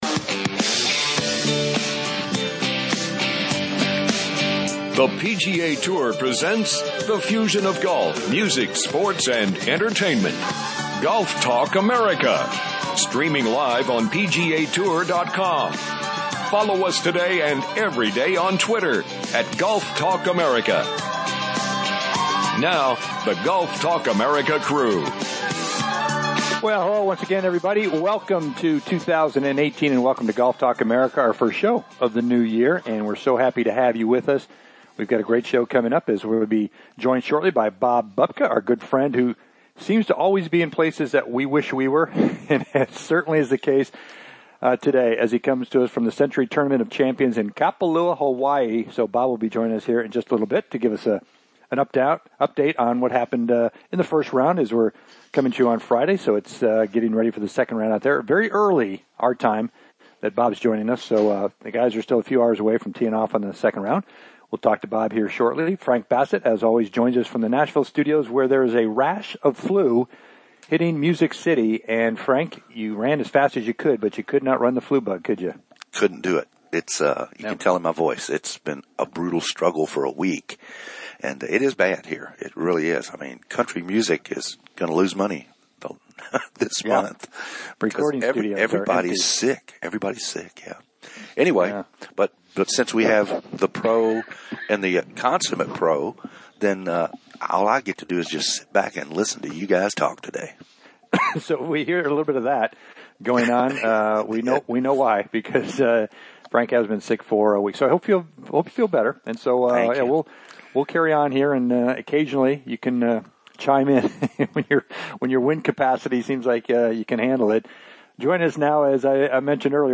"LIVE" from The Sentry Tournament of Champions